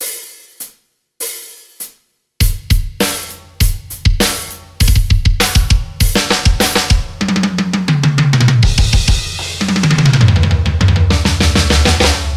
:laugh3: Вложения Drum_Set.mp3 Drum_Set.mp3 248,1 KB · Просмотры: 192